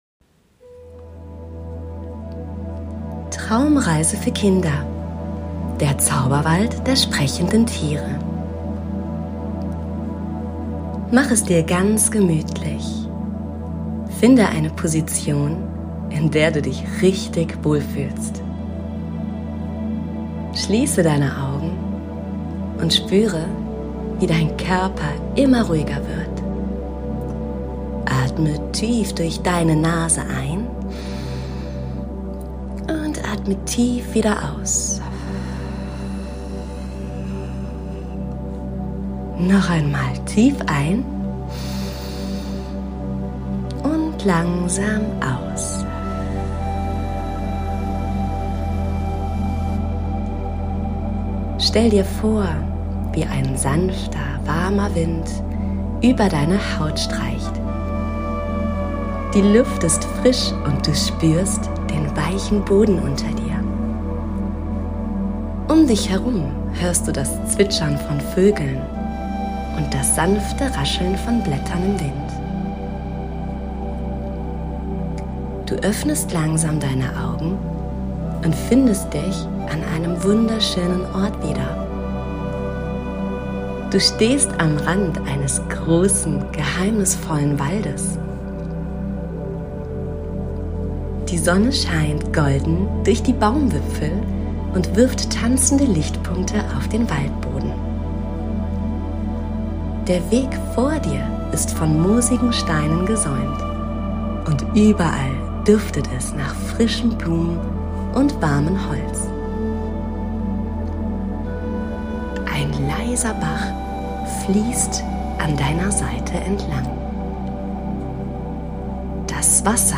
Diese geführte Fantasiereise lädt dich ein, zur Ruhe zu kommen, loszulassen und deine Vorstellungskraft zu entfalten. Perfekt zum Einschlafen oder einfach für eine kleine Pause zwischendurch.